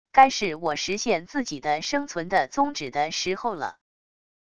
该是我实现自己的生存的宗旨的时候了wav音频生成系统WAV Audio Player